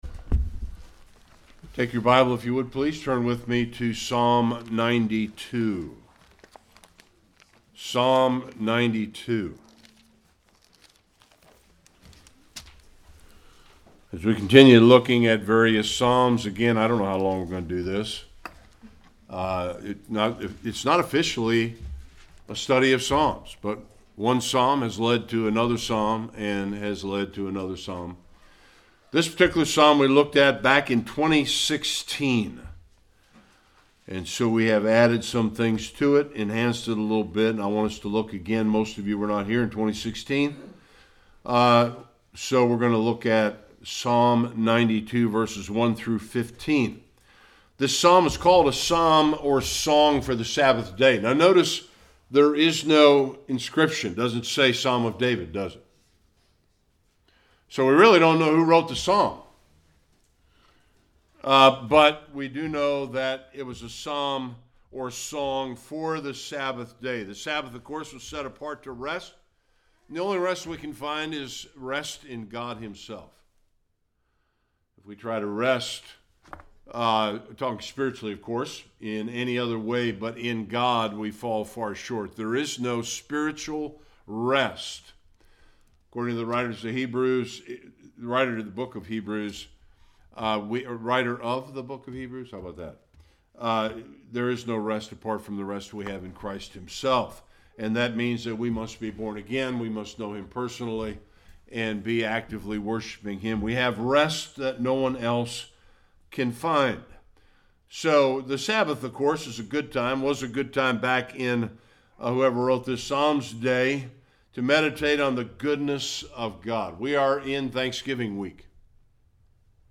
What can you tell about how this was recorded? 1-15 Service Type: Sunday Worship There are many reasons to thank